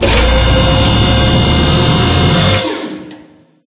Machine.ogg